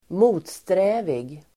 Uttal: [²m'o:tsträ:vig]